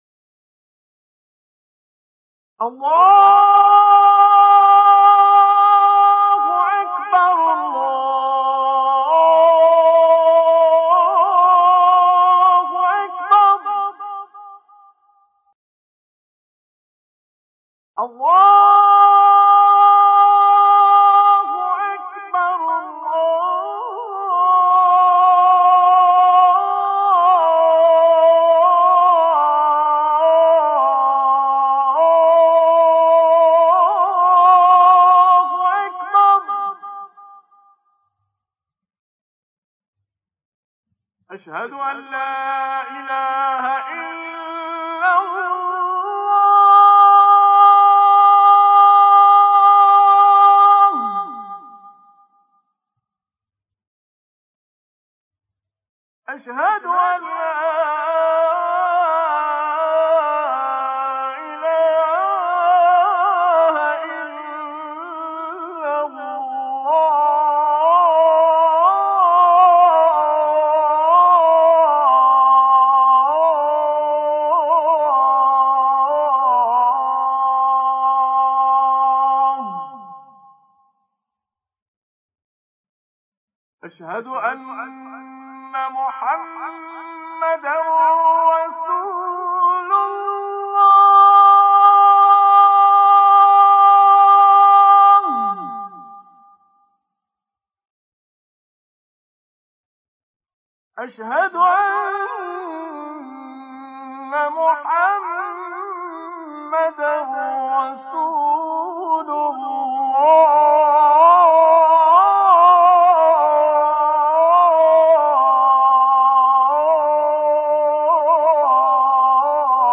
اذان